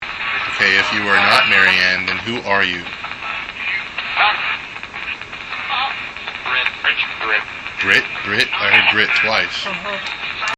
Electronic Voice Phenomena (EVP), Audio Recordings